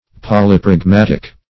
Meaning of polypragmatic. polypragmatic synonyms, pronunciation, spelling and more from Free Dictionary.
Search Result for " polypragmatic" : The Collaborative International Dictionary of English v.0.48: Polypragmatic \Pol`y*prag*mat"ic\, Polypragmatical \Pol`y*prag*mat"ic*al\, a. [Poly- + pragmatic, -ical.]